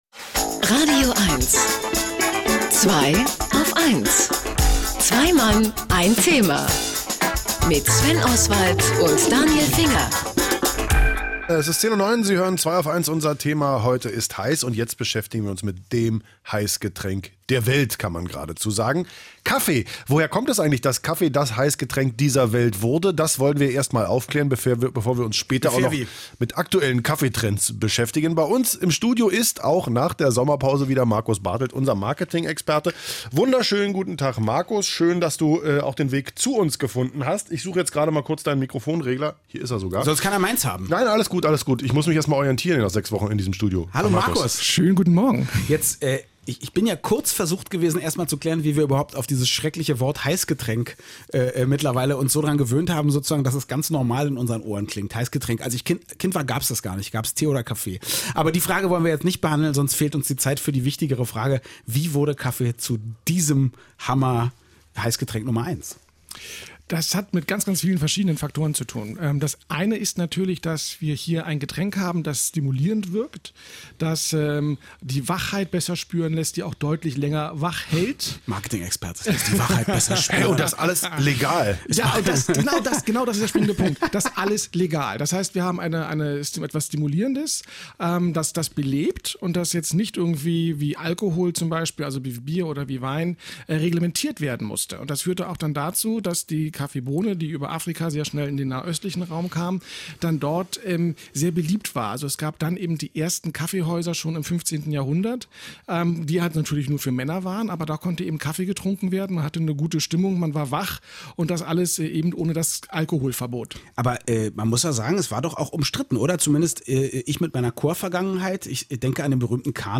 Das radioeins-Interview lässt sich hier nachhören: